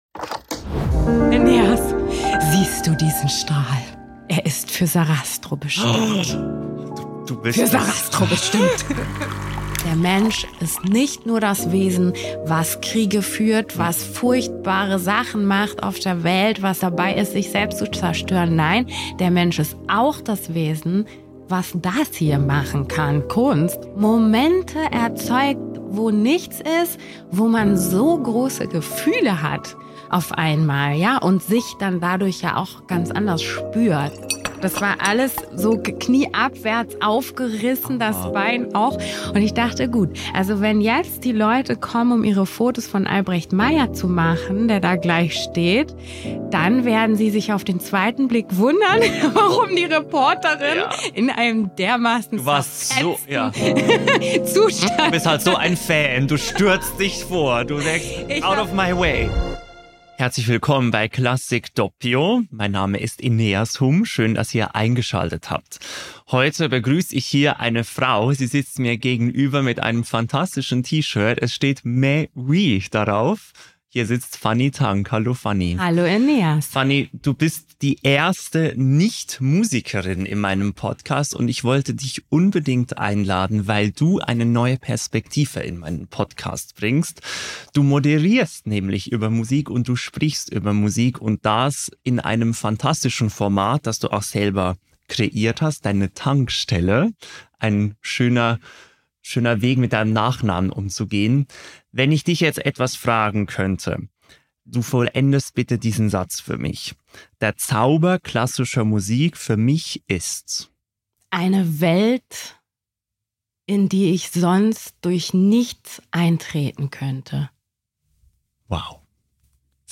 Bei einem Flat White der Berliner Röststätte sprechen wir über Neugier, über das Zuhören als Kunstform – und über ein Interview in der Berliner Philharmonie, das eine unerwartet blutige Wendung nahm.